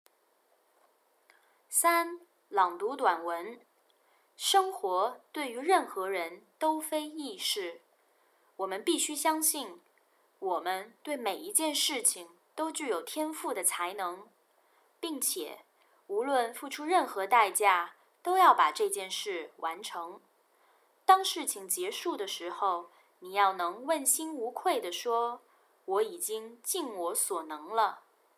Task 3 Passage Reading
Beijing Sample: